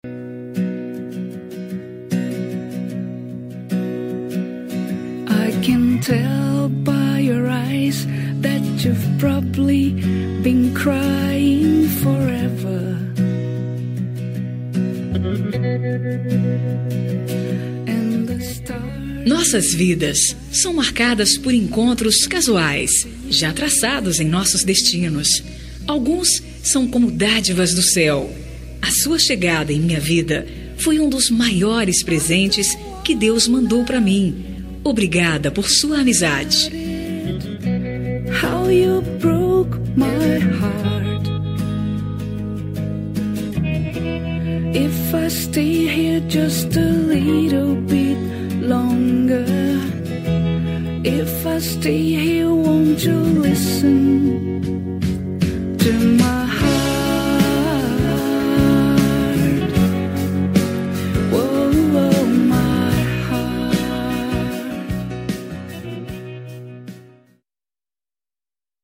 Telemensagem de Amizade – Voz Feminina – Cód: 4085